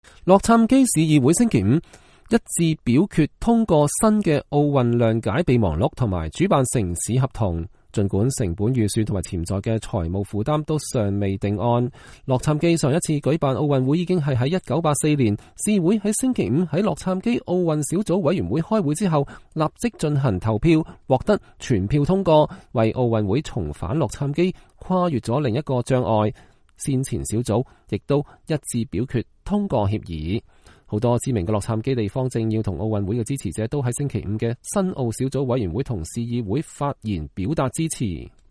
洛杉磯市長（演講者）、市議員和運動員歡迎2028奧運